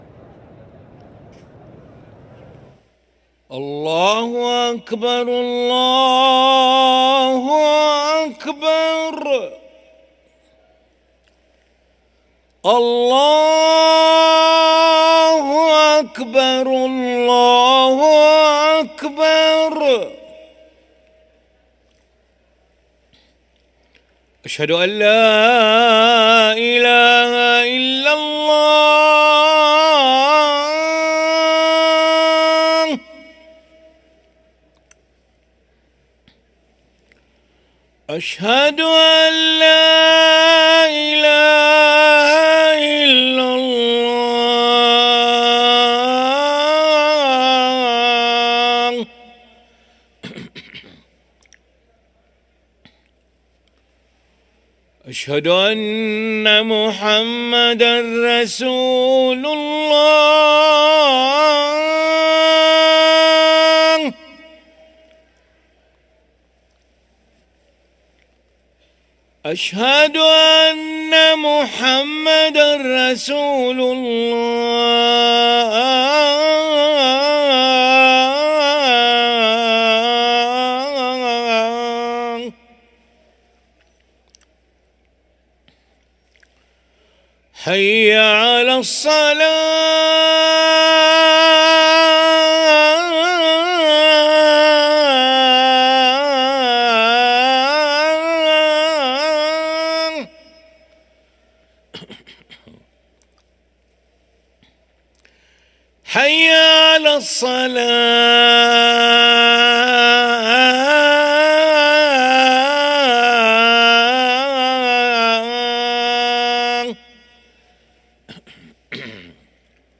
أذان العشاء للمؤذن علي ملا الخميس 16 جمادى الأولى 1445هـ > ١٤٤٥ 🕋 > ركن الأذان 🕋 > المزيد - تلاوات الحرمين